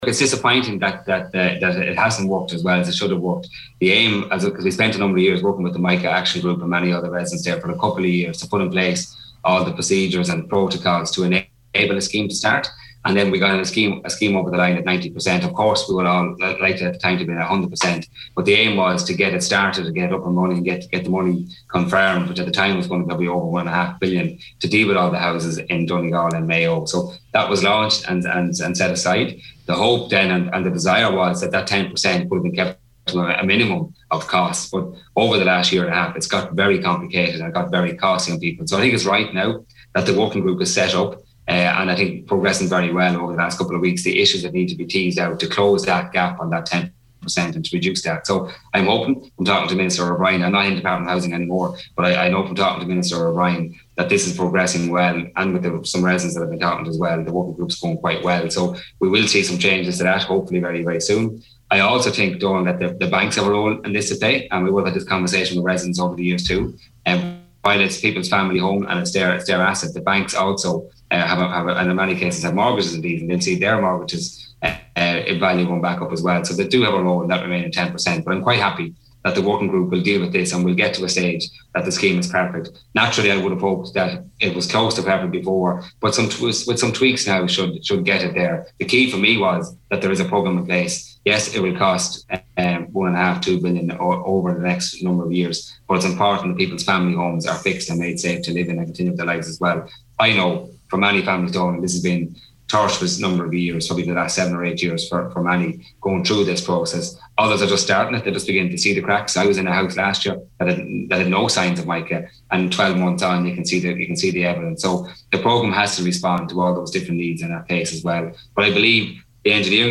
Minister English, who is now Minister of State for Business, Employment and Retail told today’s Nine til Noon Show that the priority is to get homes fixed: